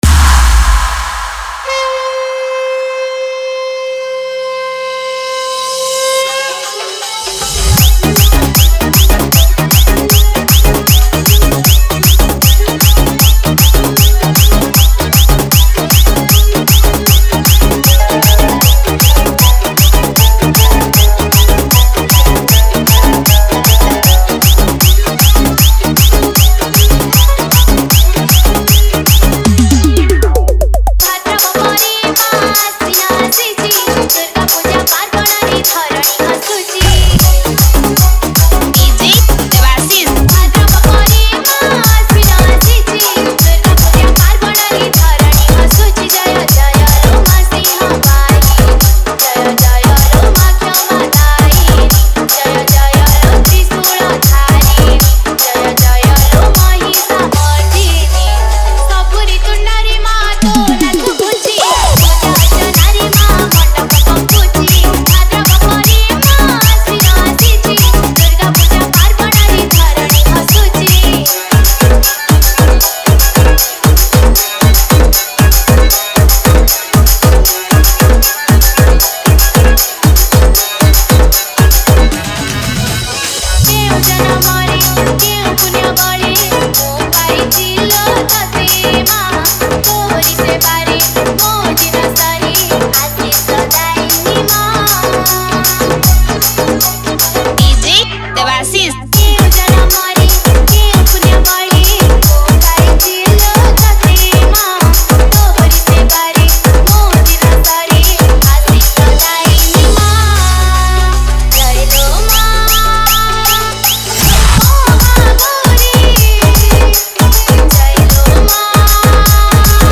Durga Puja Special Dj Song
Bhajan Dj Remix